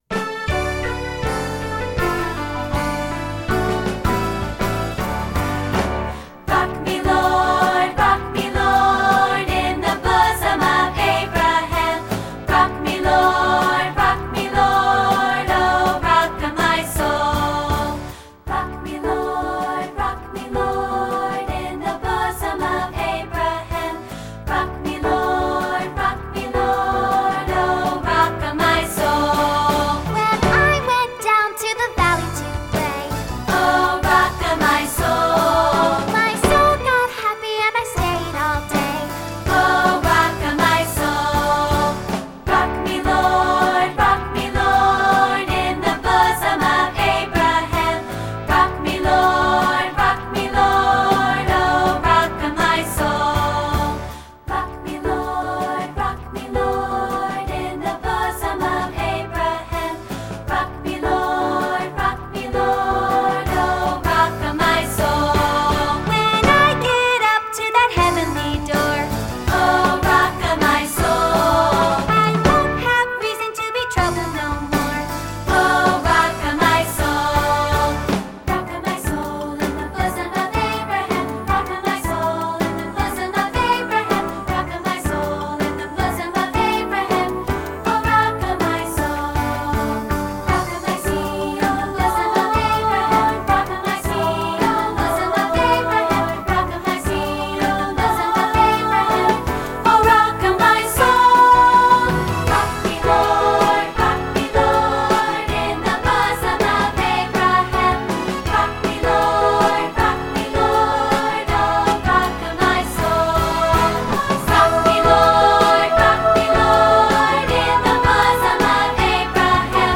secular choral
2-part, sample